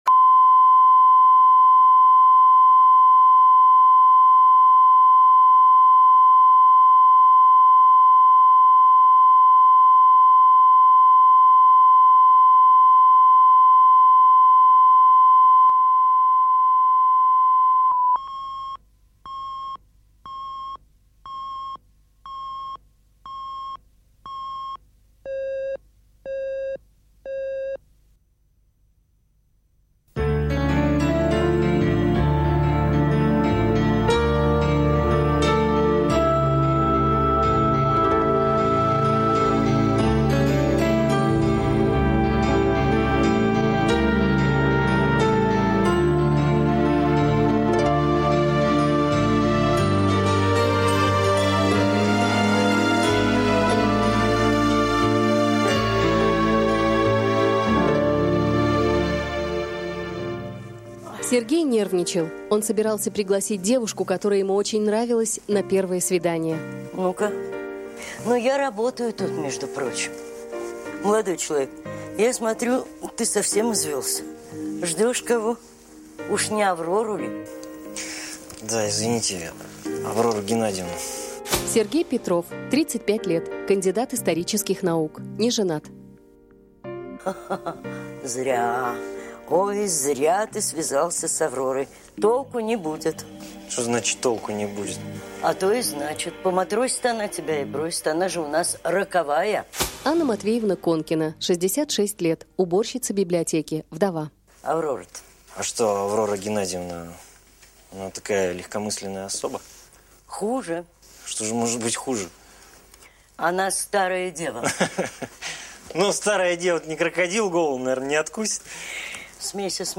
Аудиокнига Роковая аврора